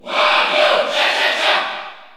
Category: Crowd cheers (SSBU) You cannot overwrite this file.
Wario_Cheer_French_PAL_SSBU.ogg.mp3